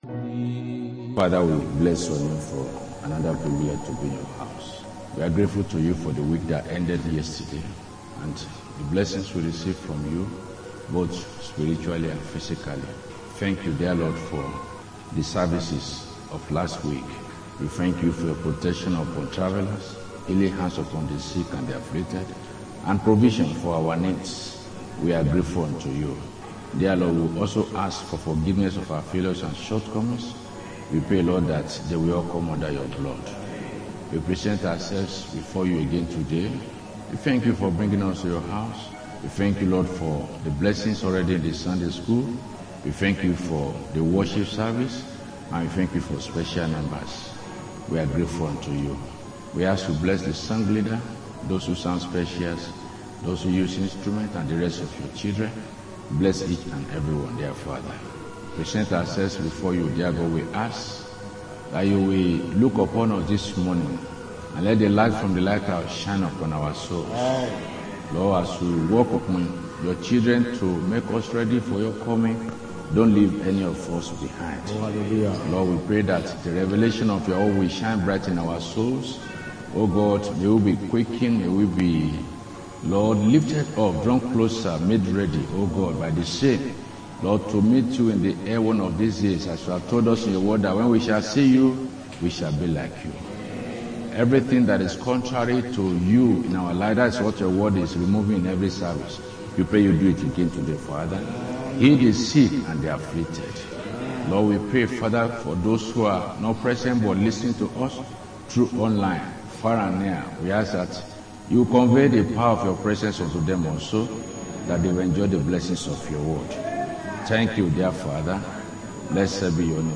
Sunday Main Service